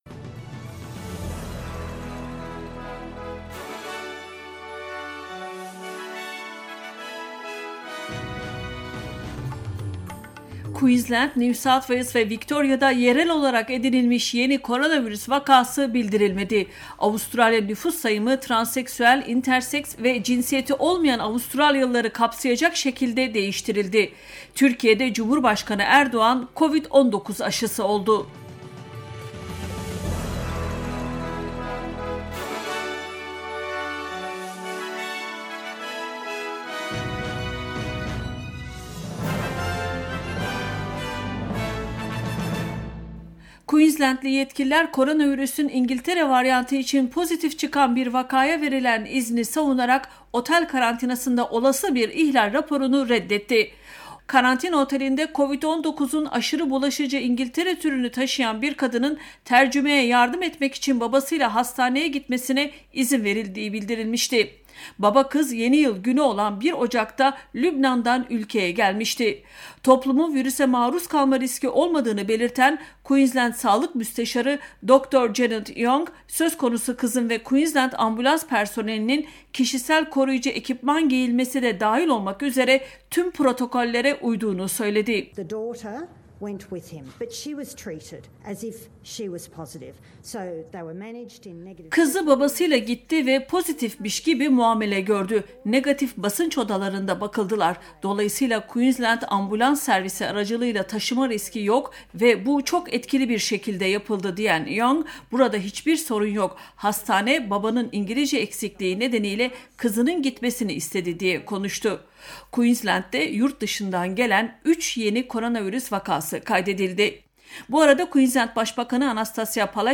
SBS Türkçe Haberler 15 Ocak